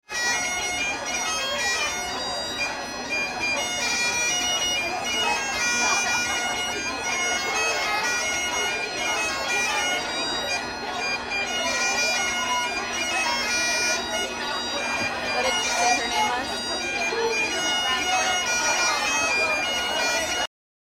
Exiting the main hall to the skirl of  bagpipes and still on a tremendous adrenaline high from all the fantastic tidbits and visuals of the last hour, I found myself facing a banquet worthy of Castle Leoch and Mrs. Fitz herself.
Bagpiper